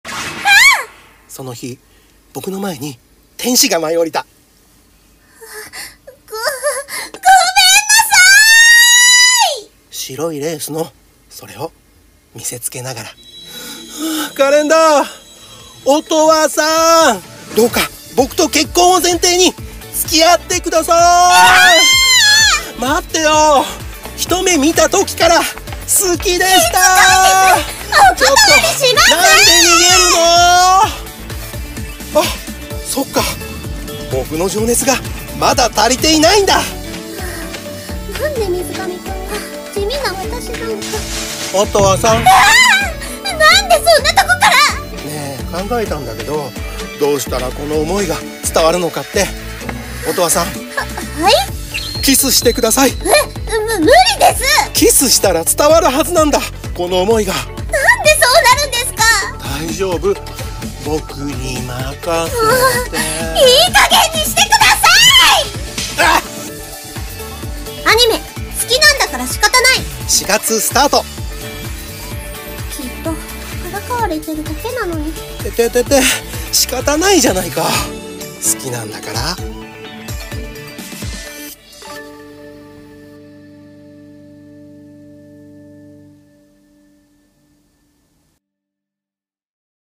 【2人声劇】好きなんだから仕方ない